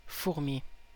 Ääntäminen
IPA : /ænt/